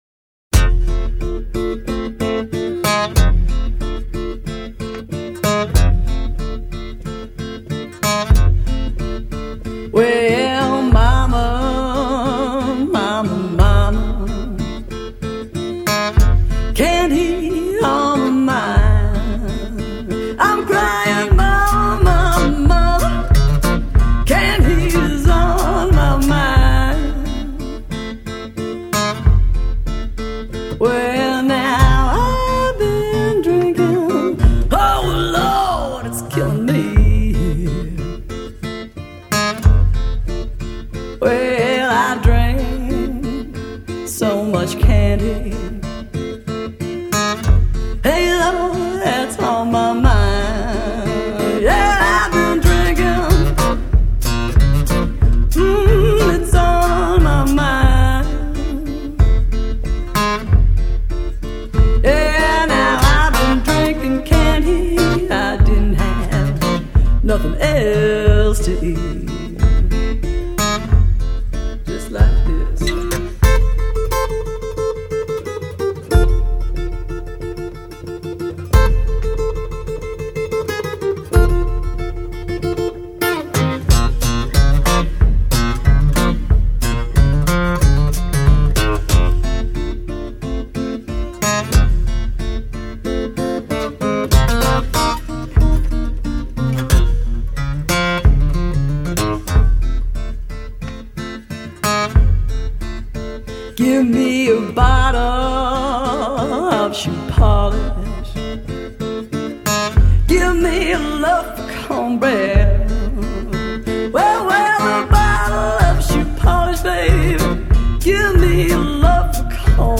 -   爵士及藍調 (295)
★ 零距離的藍調熱力，融合傳統與創新的現代風華！
★ 鮮活瞬間兼具真實自然音色，震撼所有聽覺細胞！